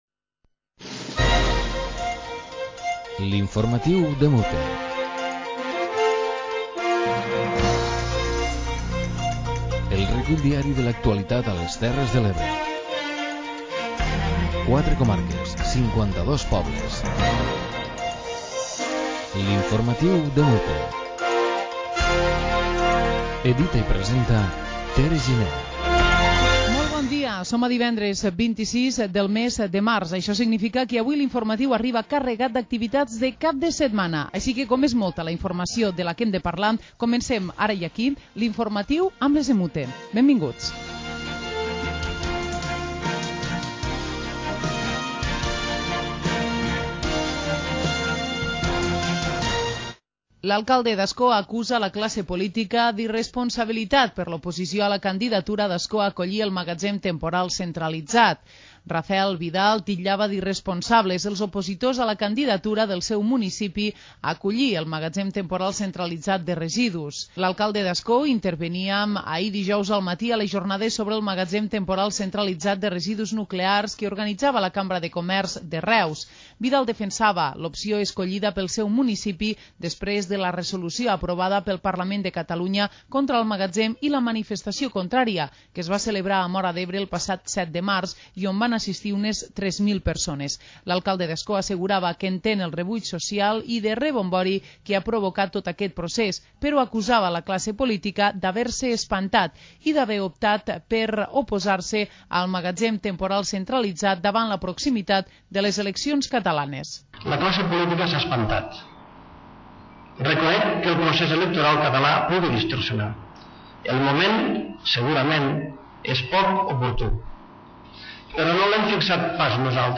Informatiu comarcal diari de les emissores municipals de les Terres de l'Ebre.